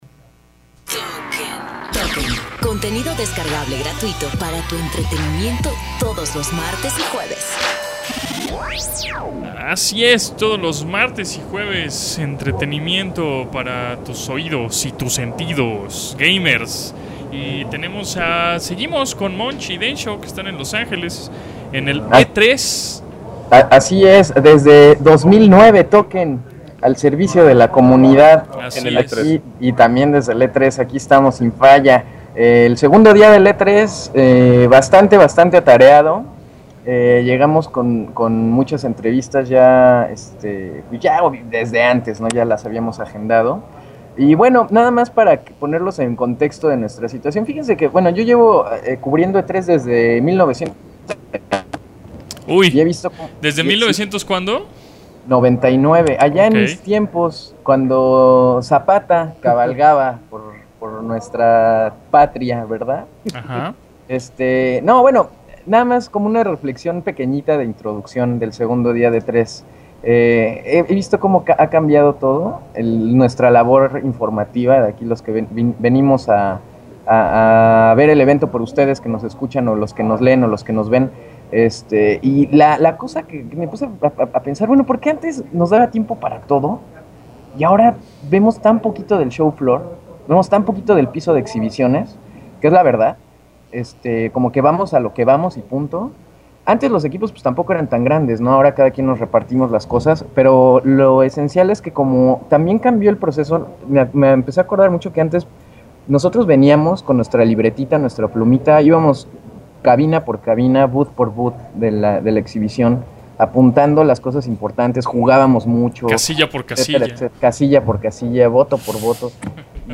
Seguimos desde el centro de convenciones en la ciudad de Los Angeles para traerles toda la información sobre el E3. Ya con 2 días dando vueltas en el piso de exhibición y con montones de citas y tiempo de juego después, venimos a contarles todo lo relevante de títulos como God of War: Ascension, Halo 4, Darksiders II, Nintendo Land, All Stars Battle Royal, Assasins Creed III y de plano hay tanta información que simplemente no tenemos los suficientes minutos para explicarles de todas las maravillas que hemos visto.